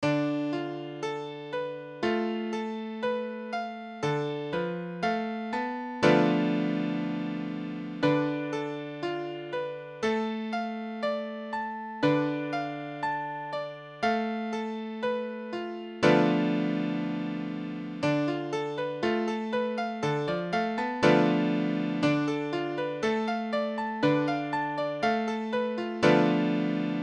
W:Dm6 Accord de Ré mineur sixième W:Forme fondamentale tTS ou tTSt : tonique tierce mineure quinte sixième majeure W:Dm6 (5 D 3 F 2 A 1 B) (1 D 2 F 3 A 5 B) W:Armature : Bb à la clef.
Dm6.mp3